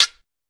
washboard_g.ogg